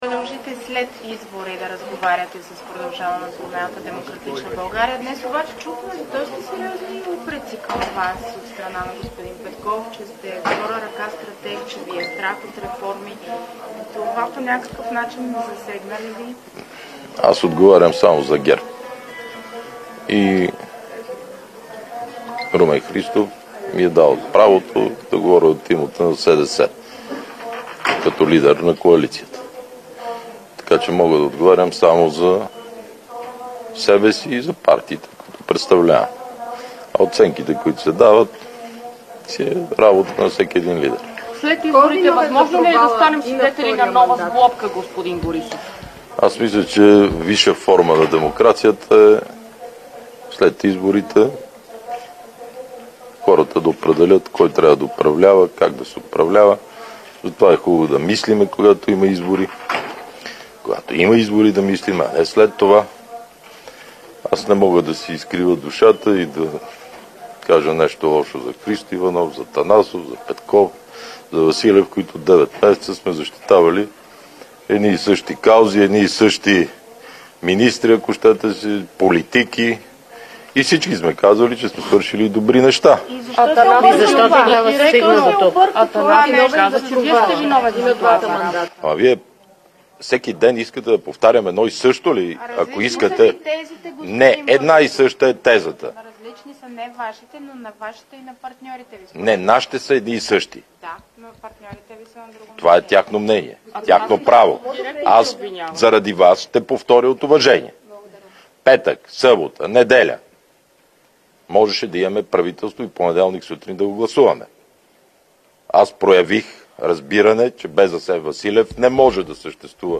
10.35 - Брифинг на председателя на БСП Корнелия Нинова за третия мандат и пресрочни избори.  - директно от мястото на събитието (Народното събрание)
Директно от мястото на събитието